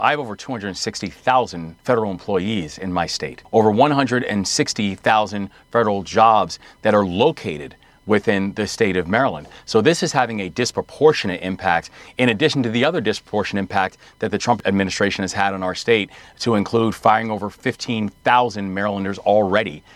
As the federal government shutdown goes into its seventh day, Maryland Governor Wes Moore continues to call for President Trump to bring an end to the situation. He spoke with CNN, saying Maryland residents who are federal workers have a lot at stake with a continued shutdown…